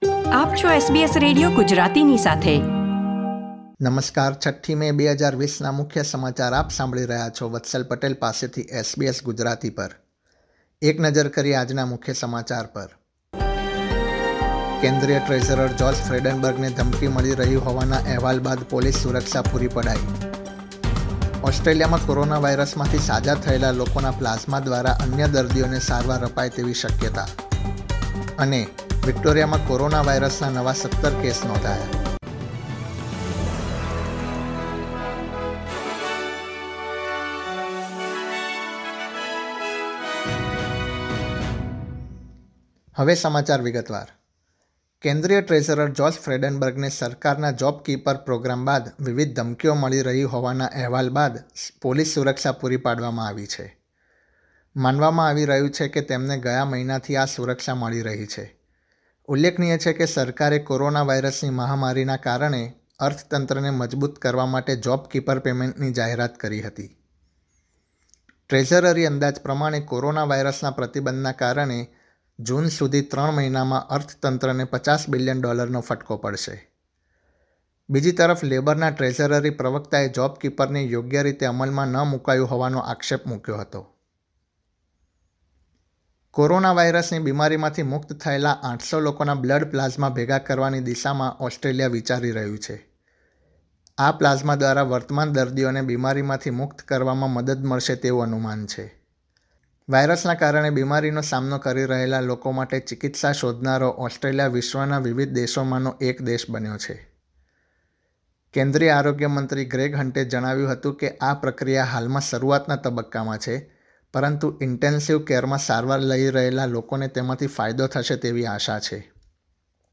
SBS Gujarati News Bulletin 6 May 2020